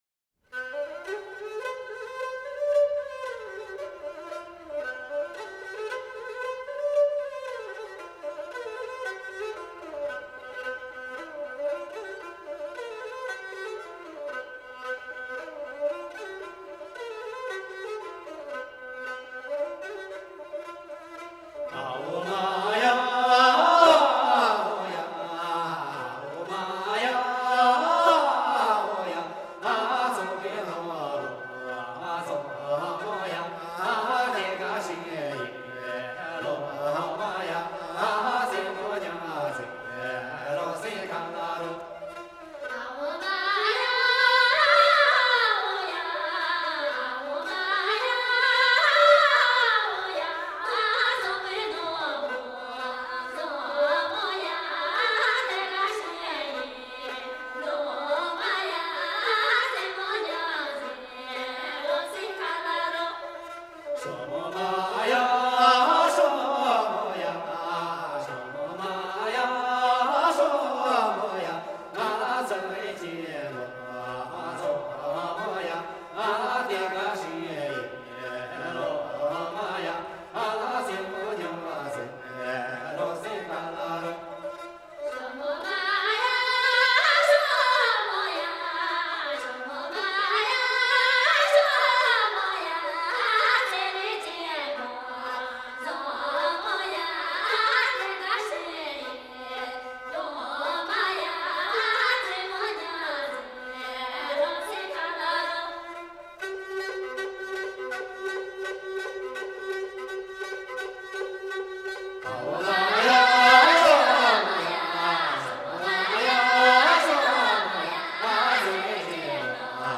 少数民族音乐系列
29首歌，旋律朴实悦耳，歌声高吭开怀，
都有嘹亮的歌声，一流的技巧。